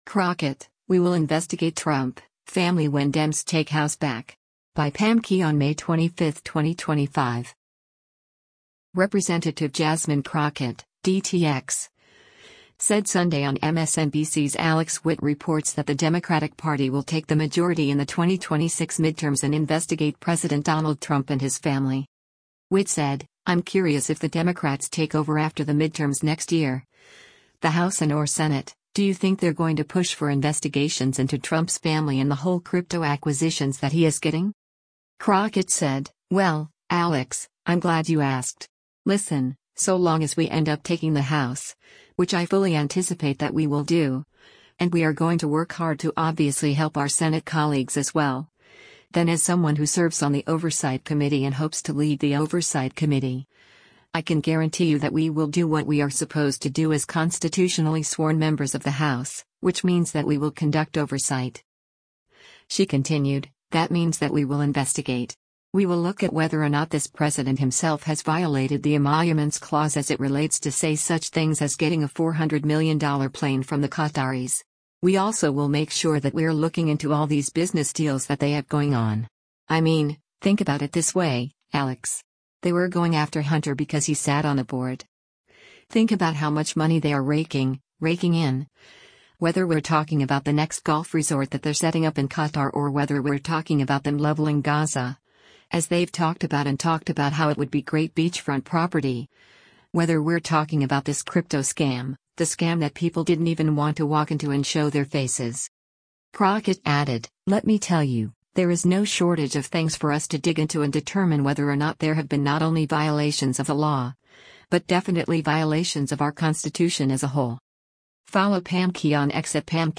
Representative Jasmine Crockett (D-TX) said Sunday on MSNBC’s “Alex Witt Reports” that the Democratic Party will take the majority in the 2026 midterms and investigate President Donald Trump and his family.